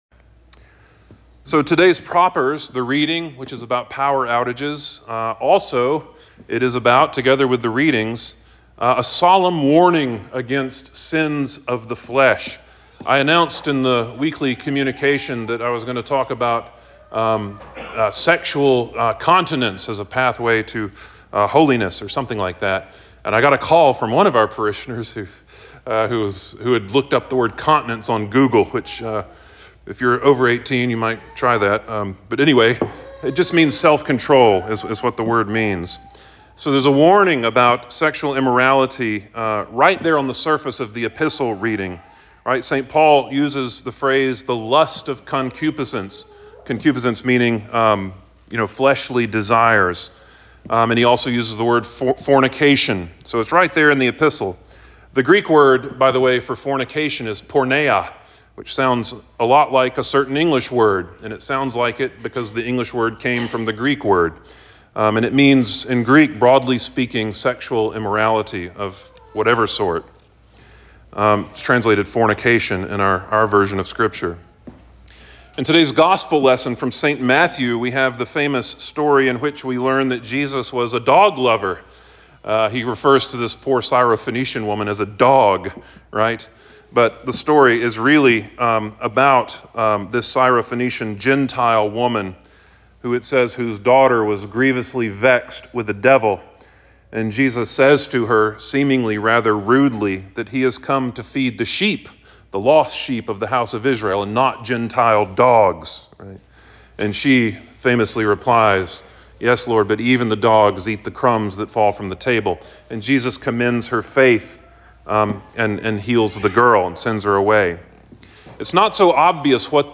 Sermon for The Second Sunday in Lent 03.16.2025